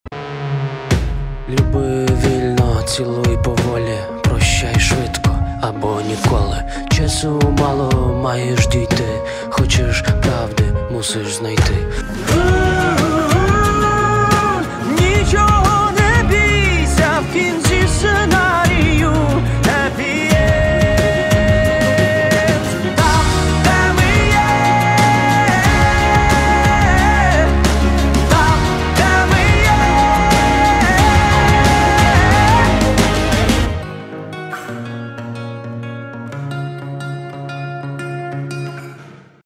• Качество: 320, Stereo
Pop Rock
поп-рок